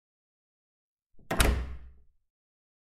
دانلود آهنگ بستن در از افکت صوتی اشیاء
جلوه های صوتی
دانلود صدای بستن در از ساعد نیوز با لینک مستقیم و کیفیت بالا